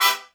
horn stab01.wav